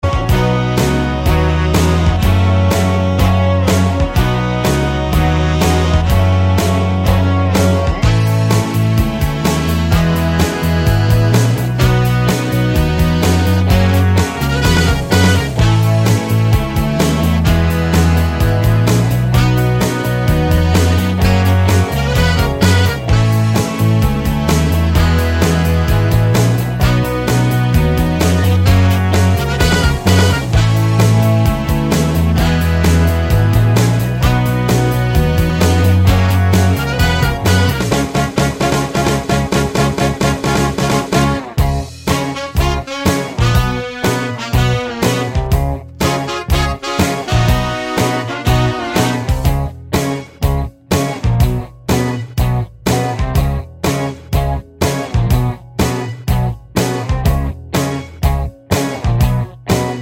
no Backing Vocals and No Crowd Pop (1980s) 3:29 Buy £1.50